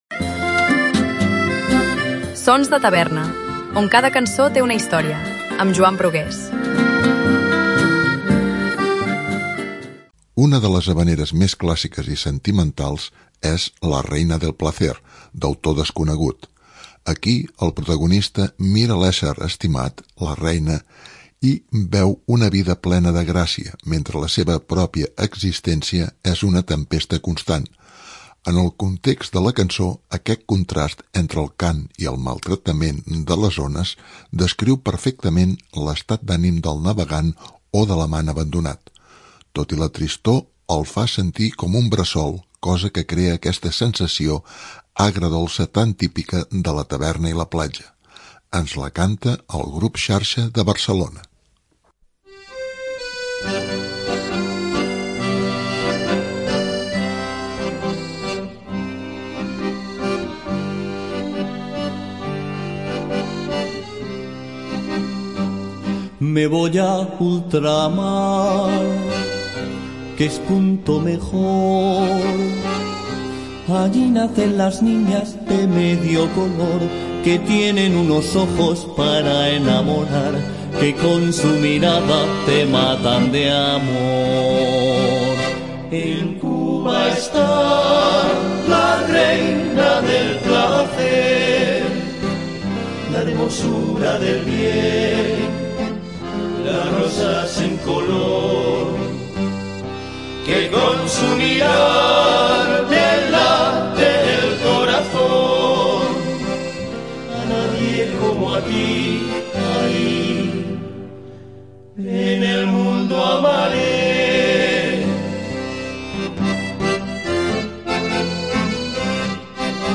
Una de les havaneres més clàssiques i sentimentals